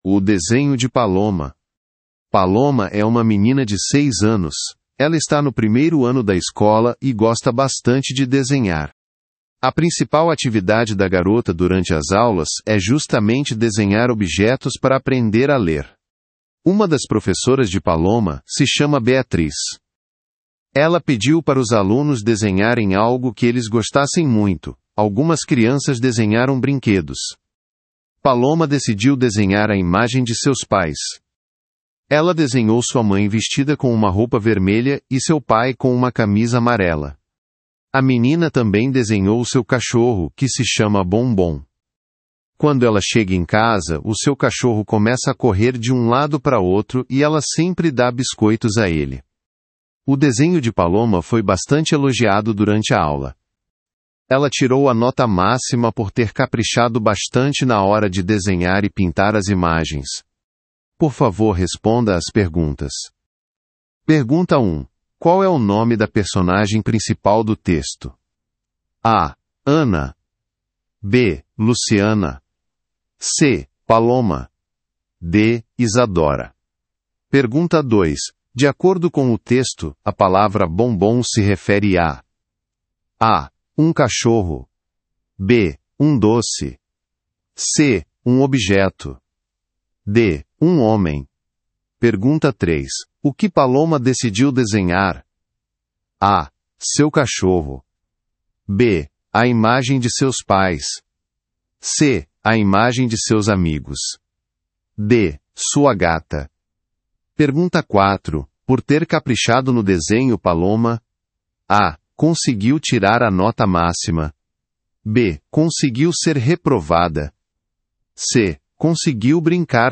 Brasil